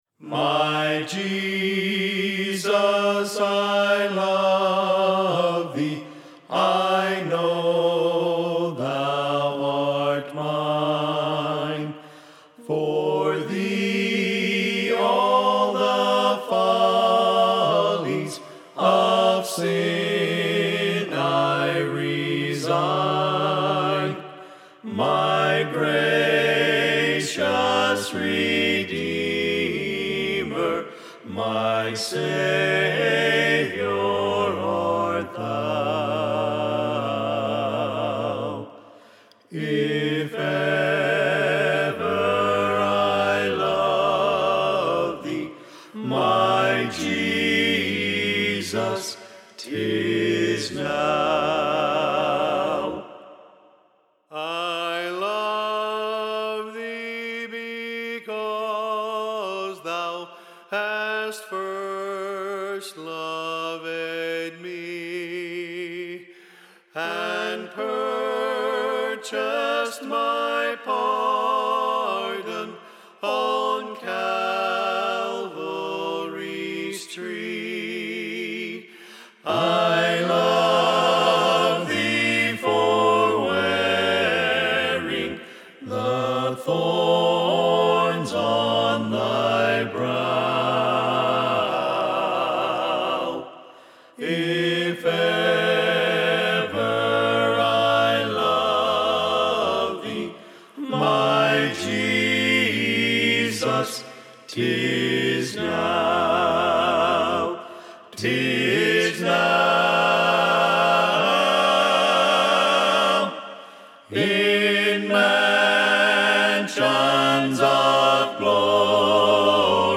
Choir Anthems for Whole Heart Concert